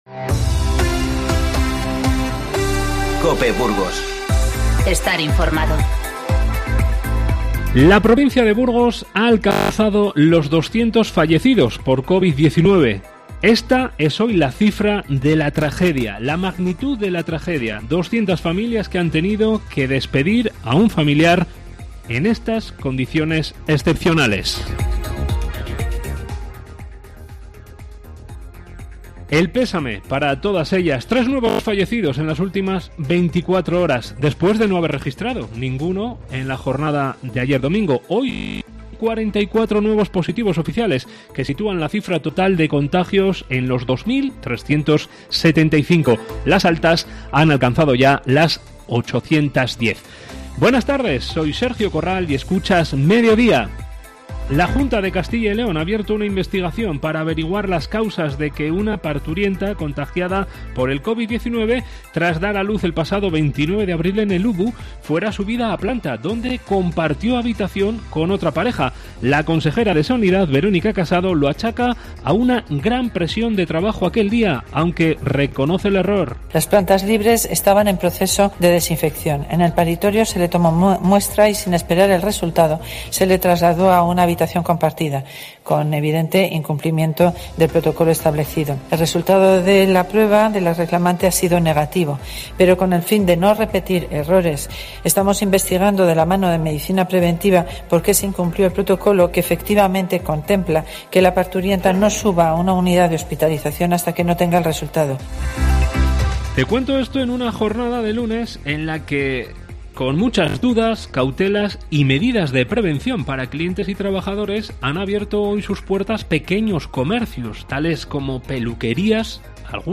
Informativo 4/5